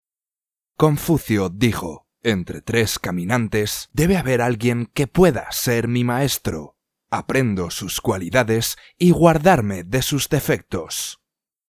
三人行 - 西语男2号 - 双讯乐音旗舰店